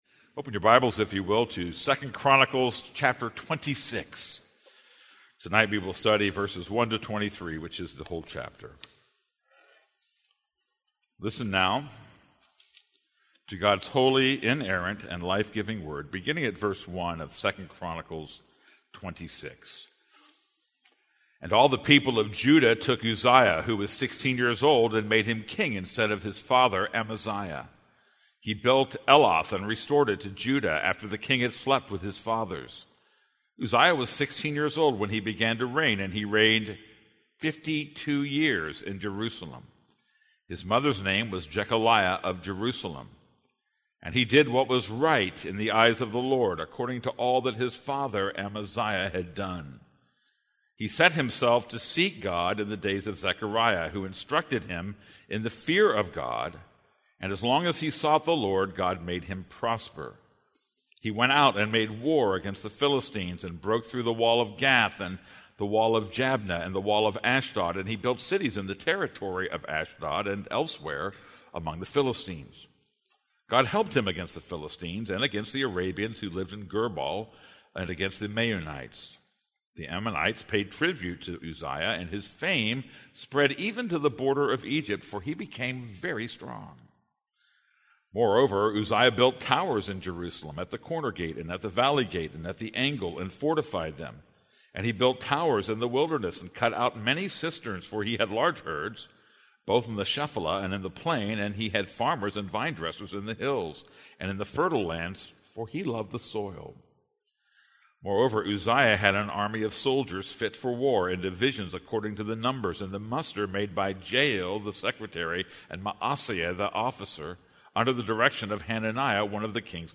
This is a sermon on 2 Chronicles 26:1-23.